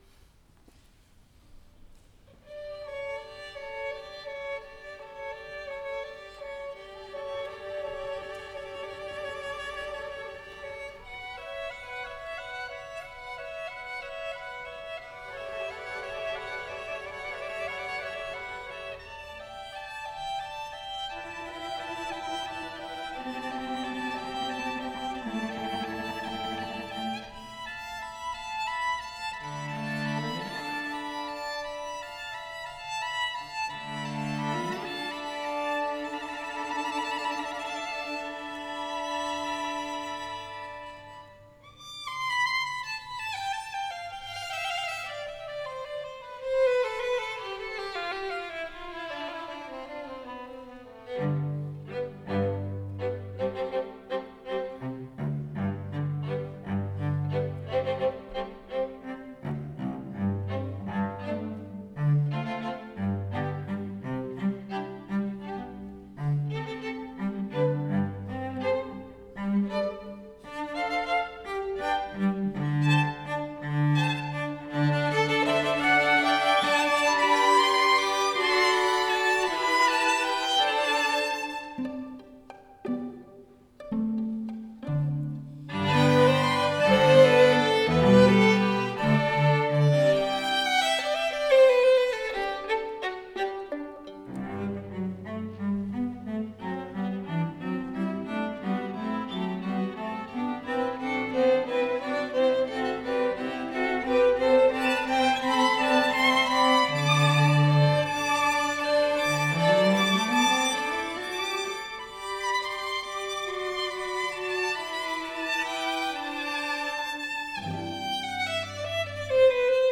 for String Quartet (2022)